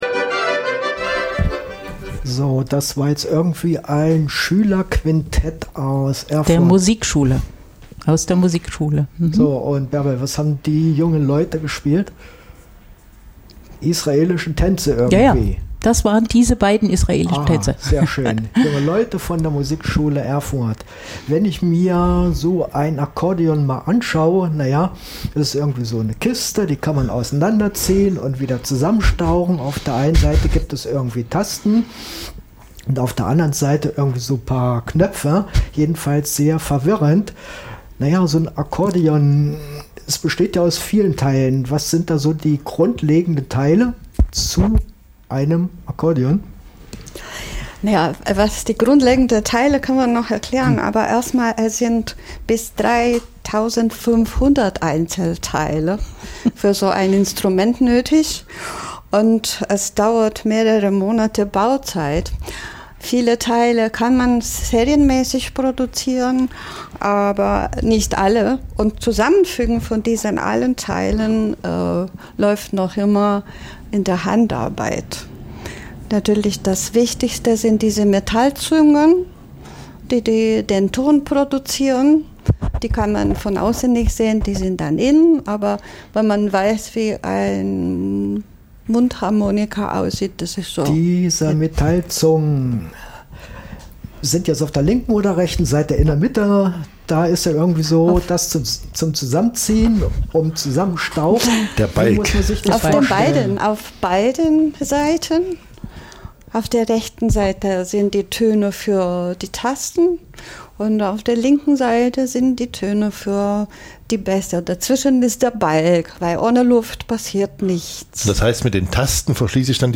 Das Informationsmagazin!